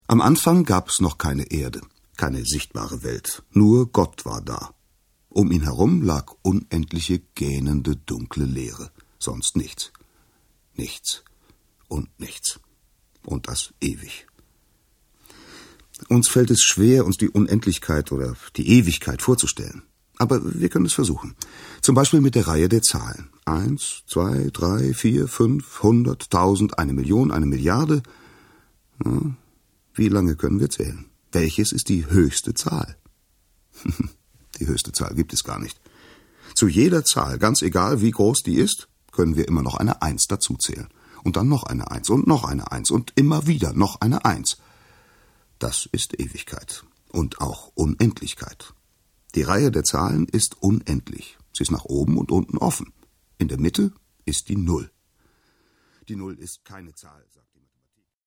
Hörbuch: Die Bibel.